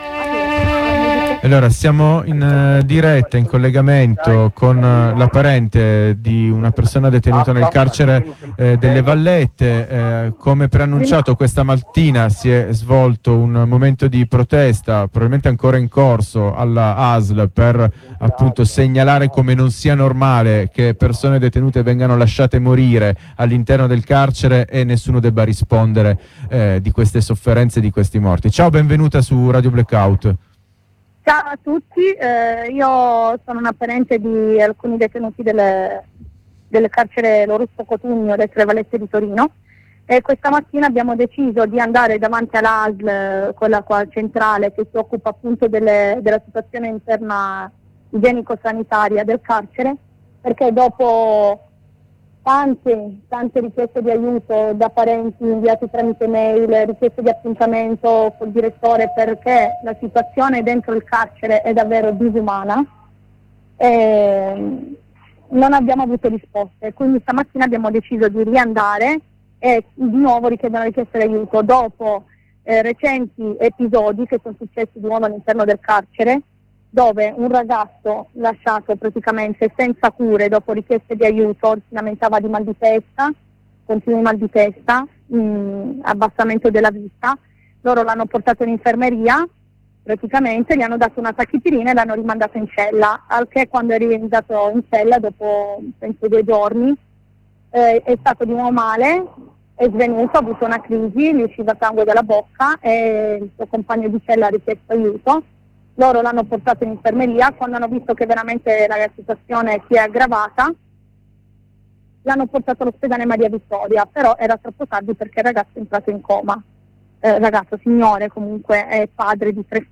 Sentiamo la voce di una donna presente alla protesta: